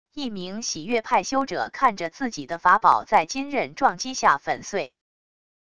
一名洗月派修者看着自己的法宝在金刃撞击下粉碎wav音频